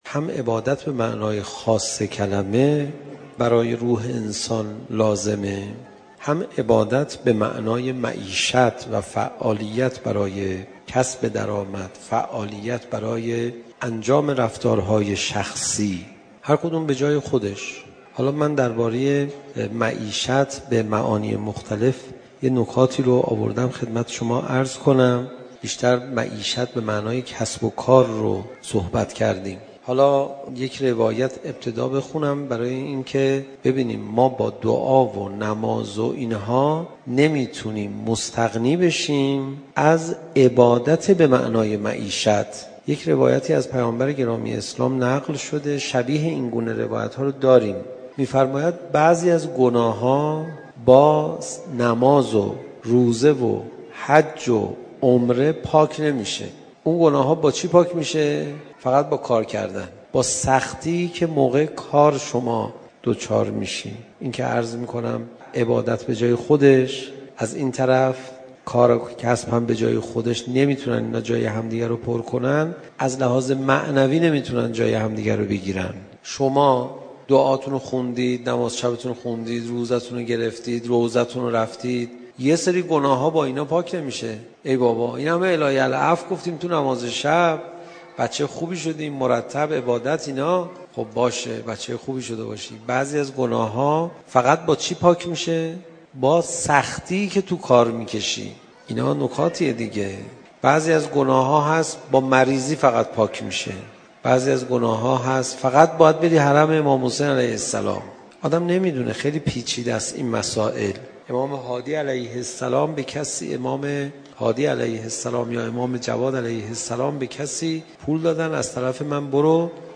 صوت سخنرانی حجت الاسلام و المسلمین علیرضا پناهیان درباره بخشودگی گناهان منتشر می شود.